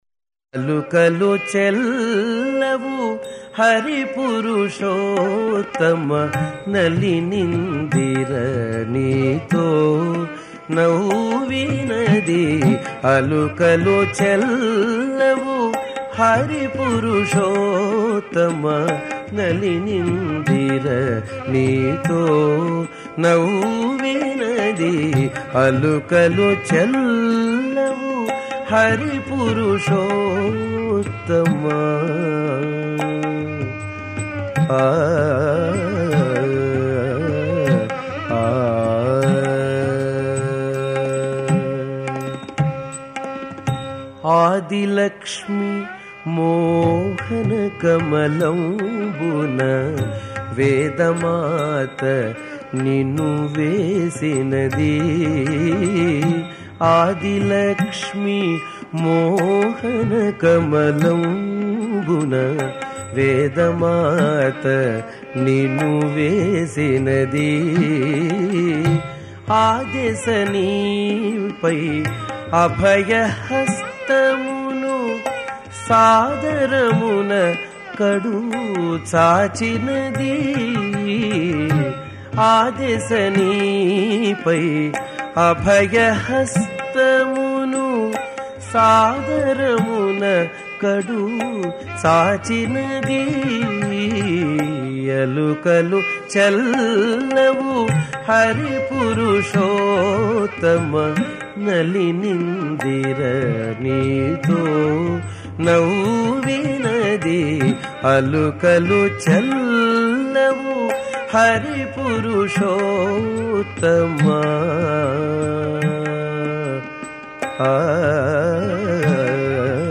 సంకీర్తన
పాడినవారు సంగీతం గరిమెళ్ళ బాలకృష్ణప్రసాద్